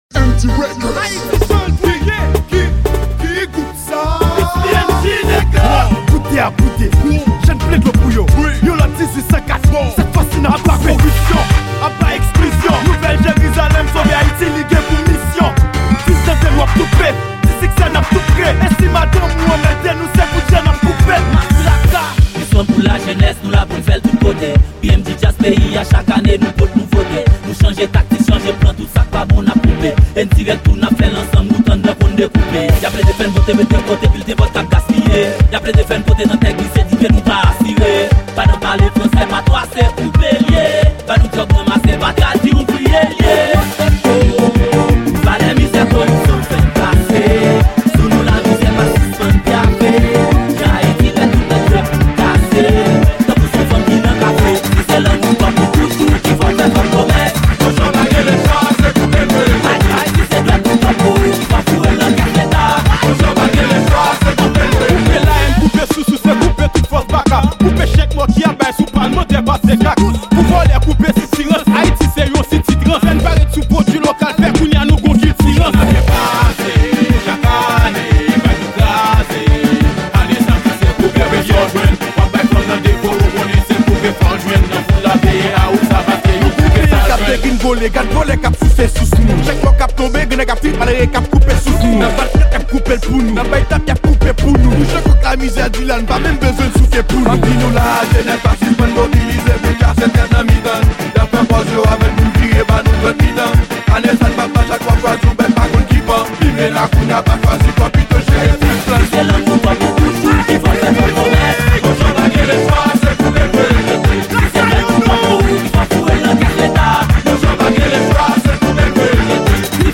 Genre : KANAVAL